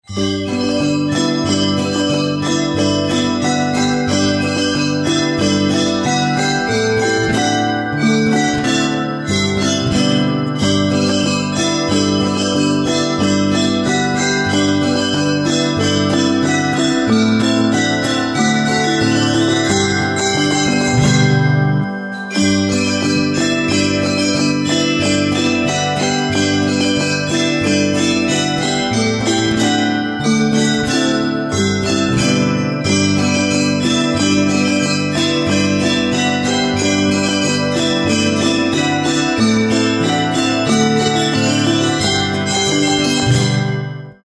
campane e campanine